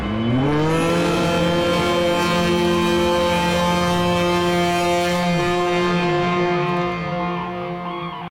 File:Nebula Roar.ogg
Nebula_Roar.ogg